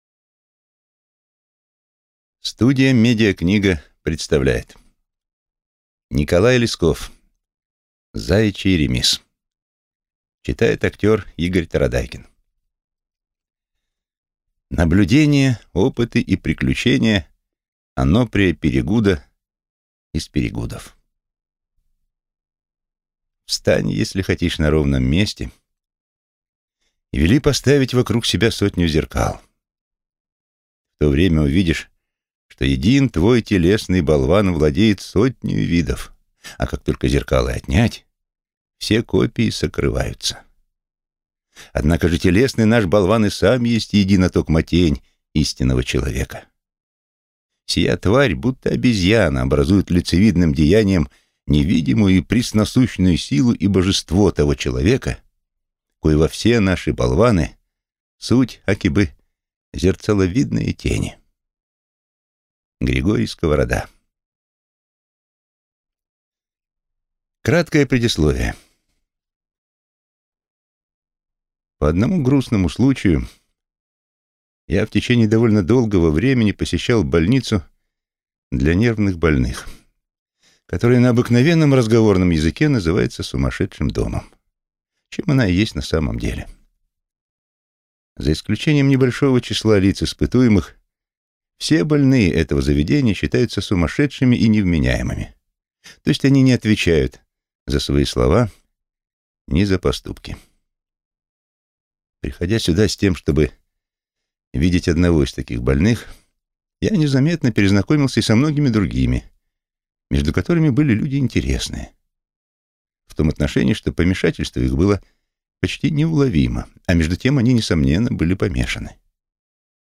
Аудиокнига Заячий ремиз | Библиотека аудиокниг